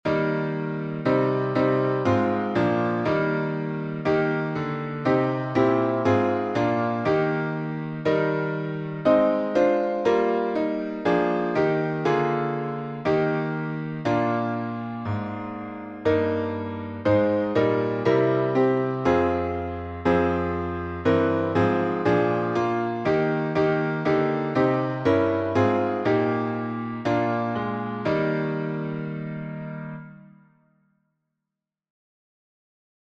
Fairest Lord Jesus — E flat, alternate two.
Key signature: E flat major (3 flats) Time signature: 4/4